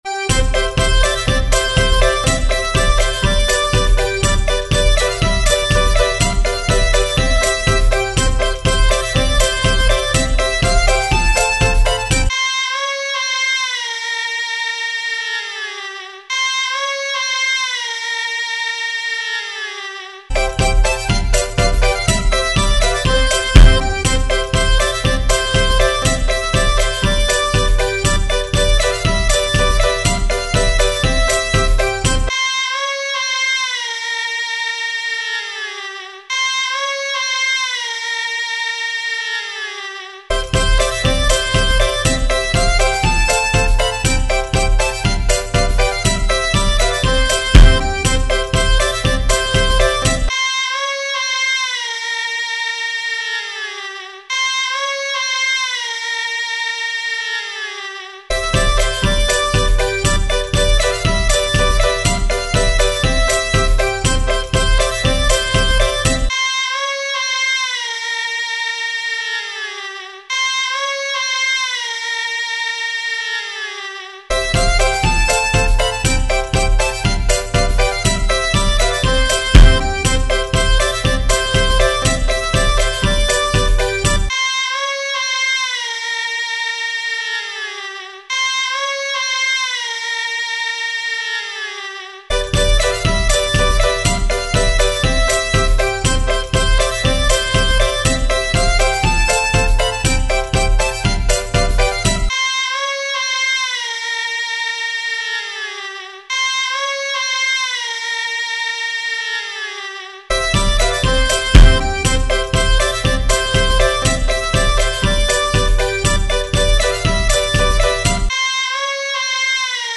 It includes a Zarunna. When the Zarunna sounds that it the single to change leaders.